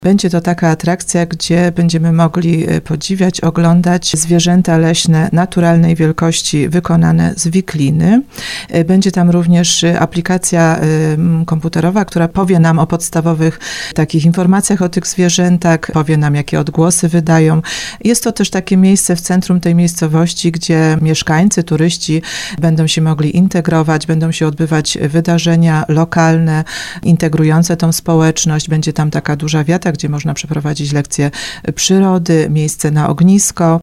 Wójt gminy Gromnik Małgorzata Kras mówiła w audycji Słowo za Słowo, że ma to być miejsce do rodzinnego spędzania czasu w otoczeniu przyrody.